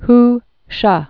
(h shŭ) 1891-1962.